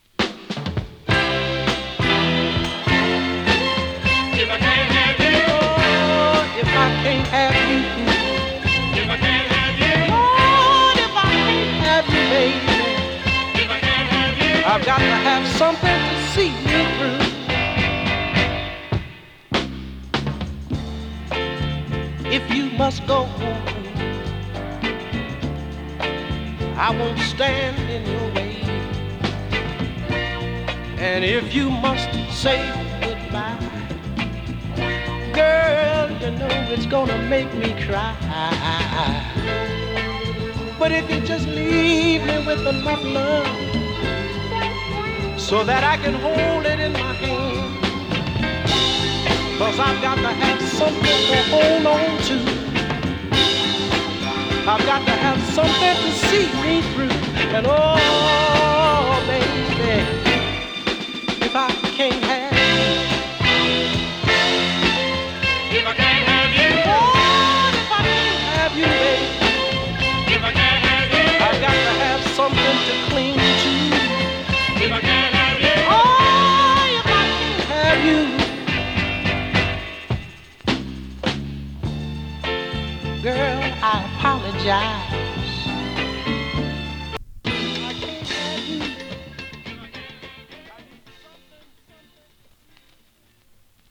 ノーザンソウル
＊音の薄い部分で稀に軽いチリパチ・ノイズ。